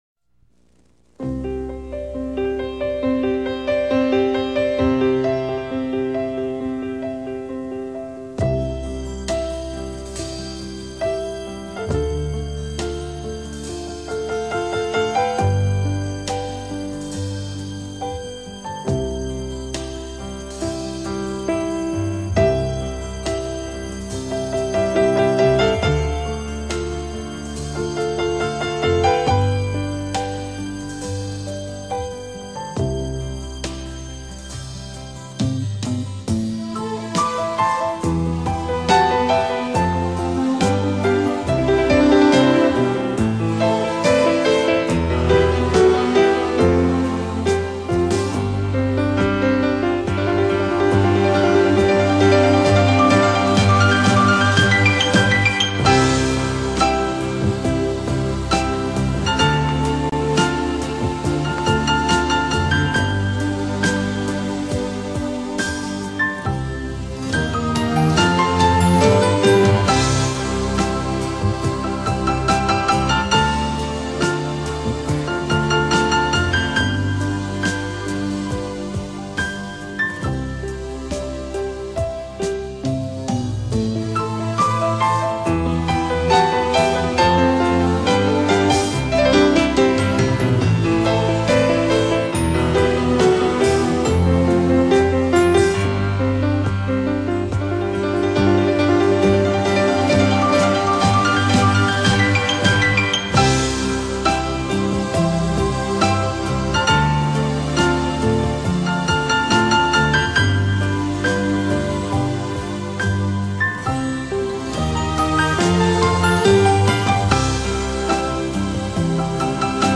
Pianista 1                                    Pianista 2